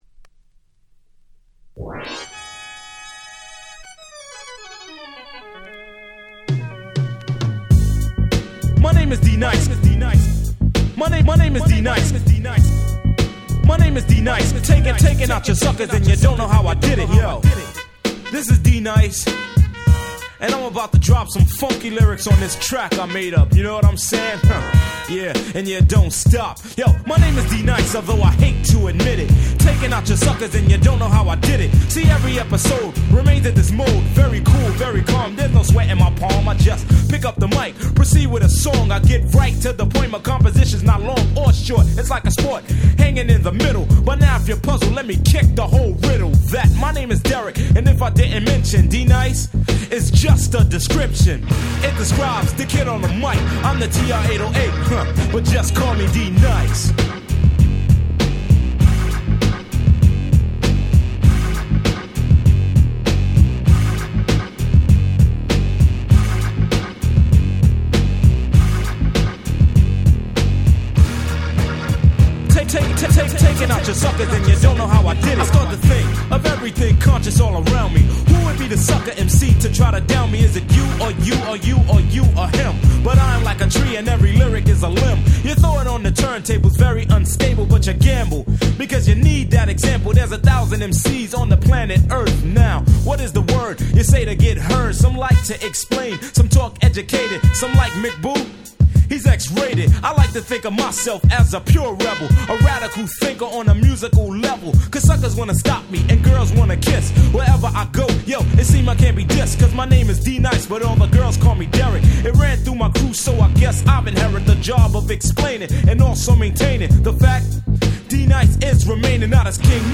90' Smash Hit Hip Hop !!
うーん！Rapがタイト！！
90's Boom Bap ブーンバップ